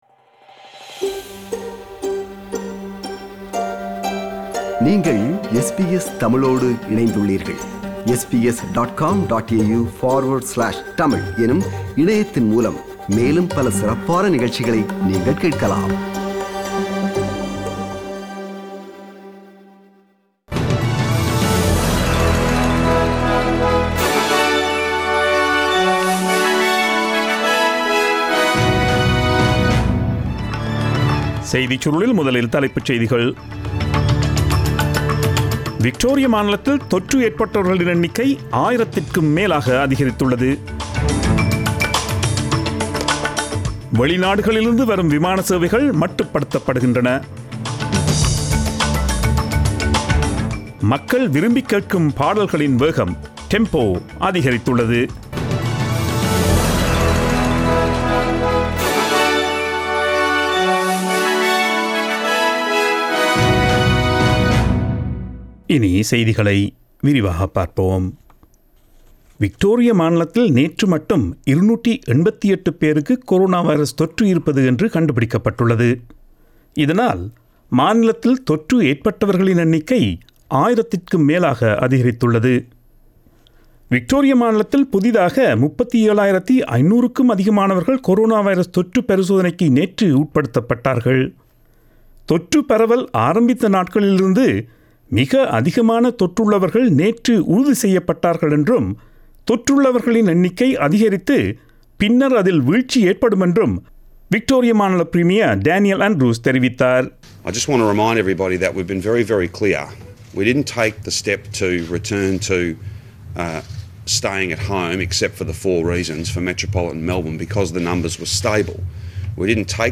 Australian news bulletin aired on Friday 10 July 2020 at 8pm.